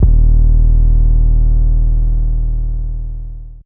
808 [Dro].wav